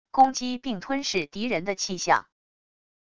攻击并吞噬敌人的气象wav音频